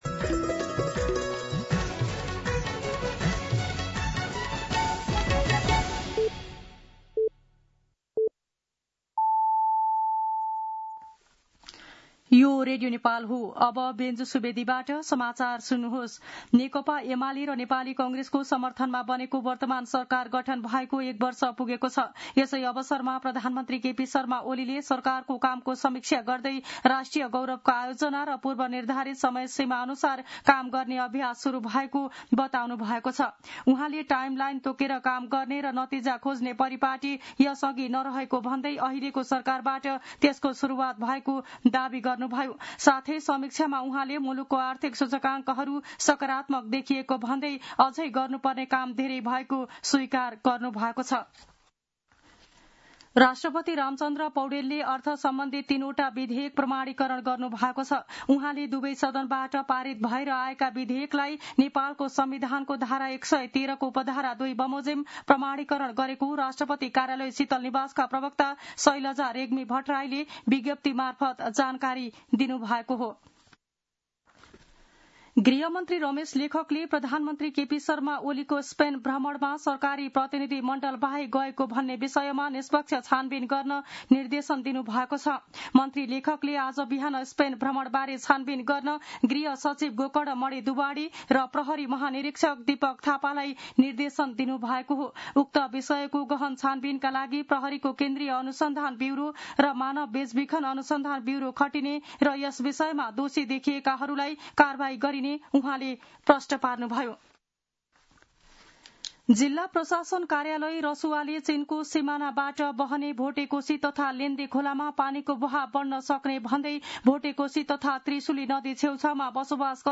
दिउँसो ४ बजेको नेपाली समाचार : ३० असार , २०८२